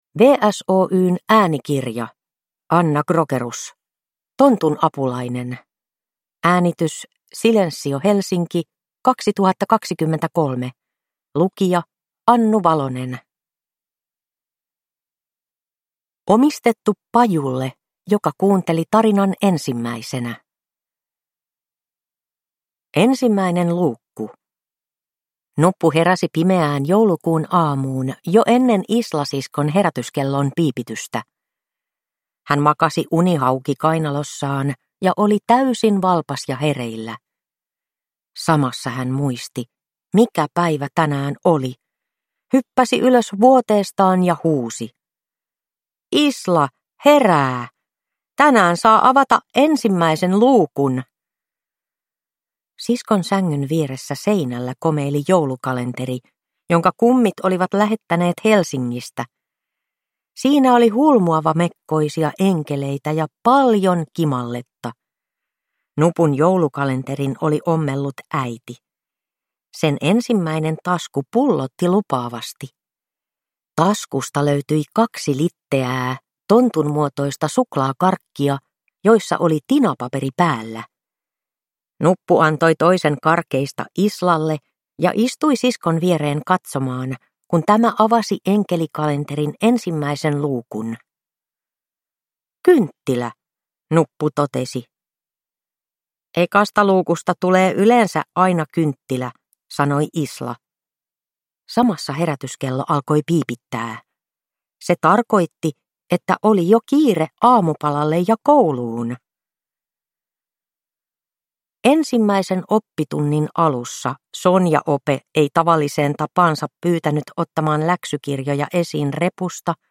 Tontun apulainen – Ljudbok – Laddas ner